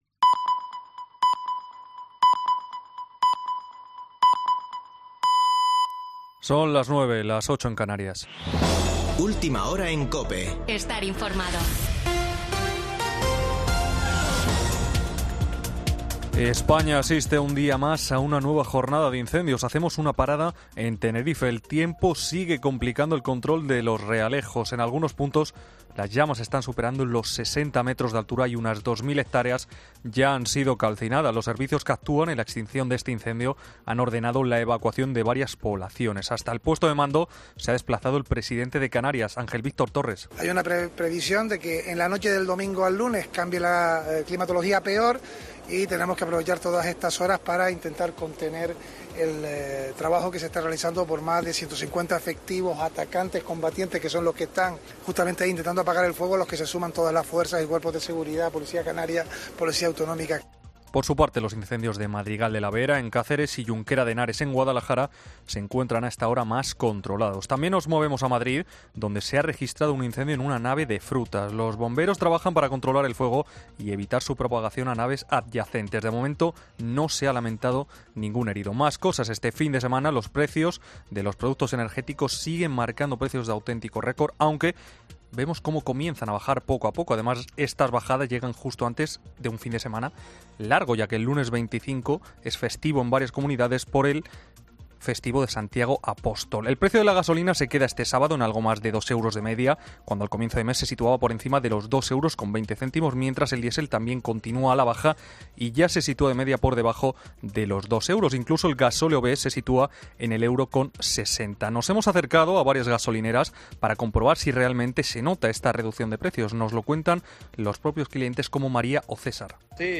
Boletín de noticias de COPE del 23 de julio de 2022 a las 21:00 horas